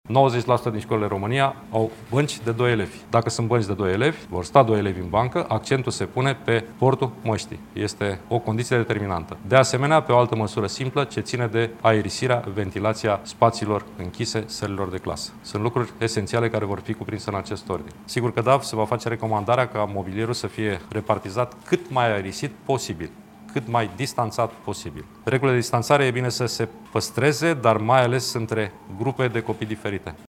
De altfel, în această după-amiază Ministrul Educației spunea că distanțarea ar trebui adaptată fiecărei școli: